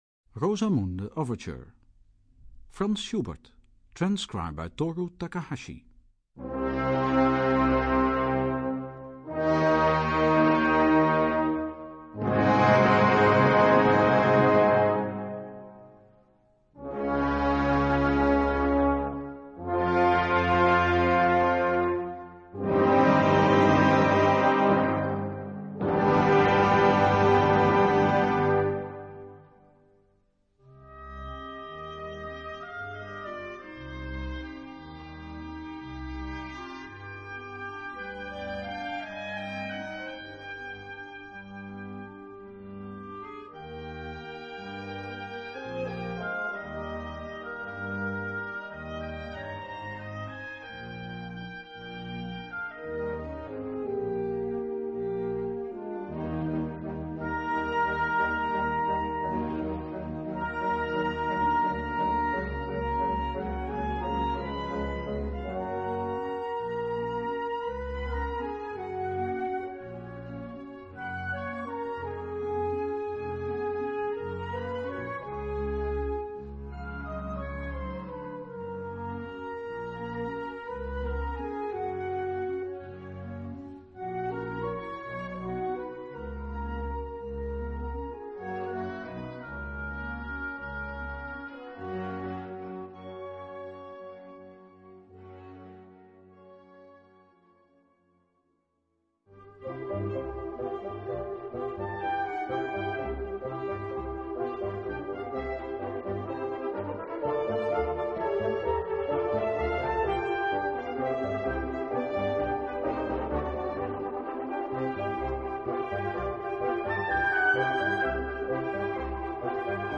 C minor 〜 C Major（原調）
編成はいわゆるウインド・アンサンブルで、つまり40名程度で演奏されるのがベストと思います。